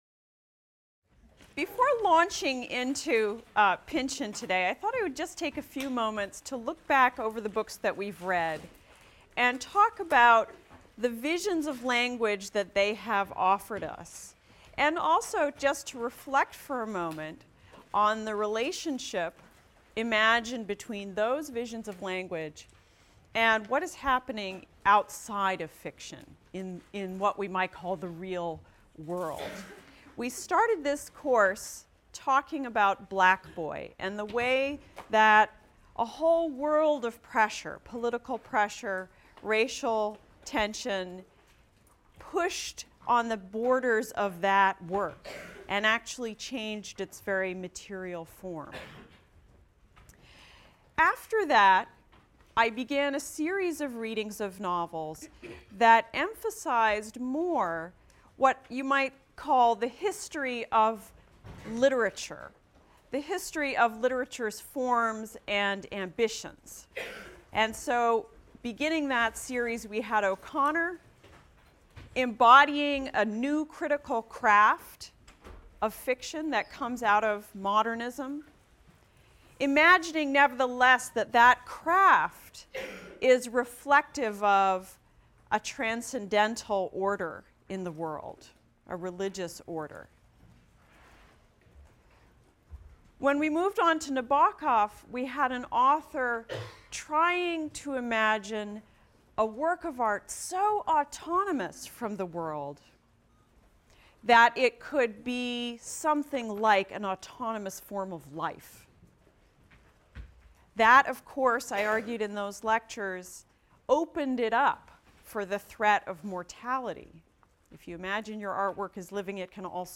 ENGL 291 - Lecture 12 - Thomas Pynchon, The Crying of Lot 49 | Open Yale Courses